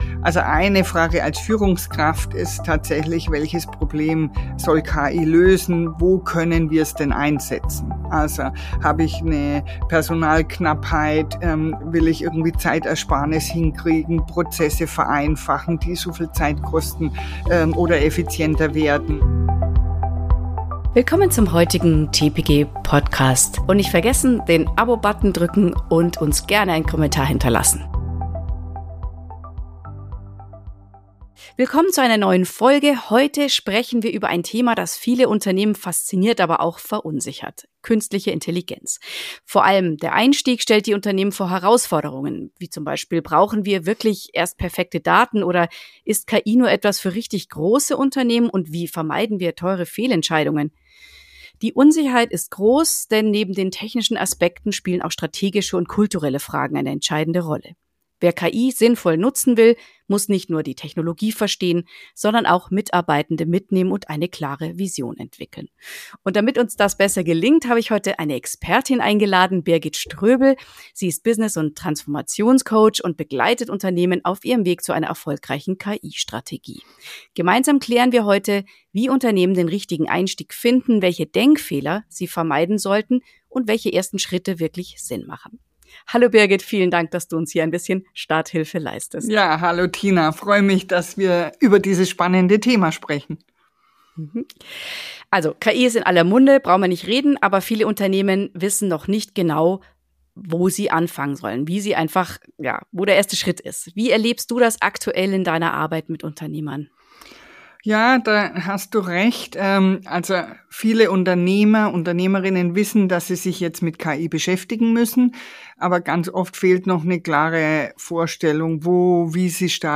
Alle 2 Wochen hören Sie im TPG Podcast Projektmanagement eine neue Episode mit wertvollem Praxiswissen zu den Themen PMO, Projekt-, Programm-, Portfolio- und Ressourcenmanagement. Lernen Sie von Interviews mit erfahrenen Personen aus der PM-Praxis.